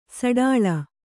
♪ saḍāḷa